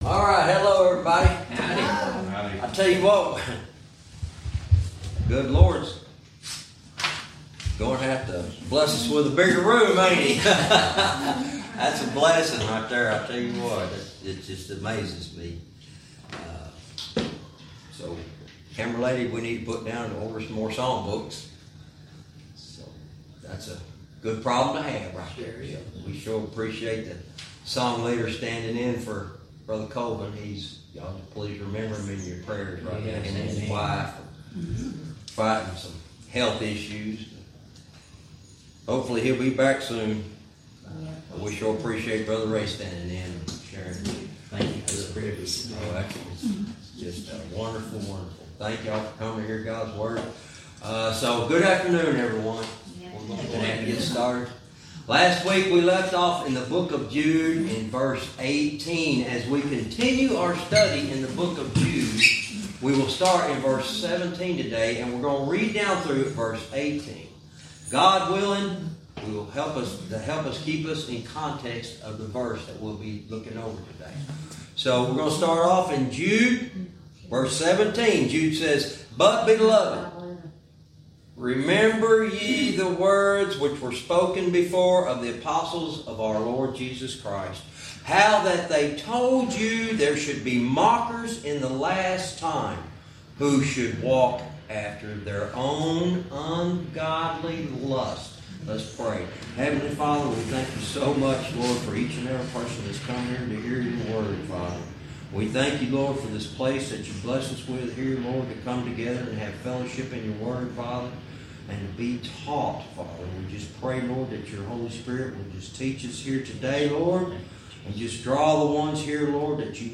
Verse by verse teaching - Jude lesson 80 verse 18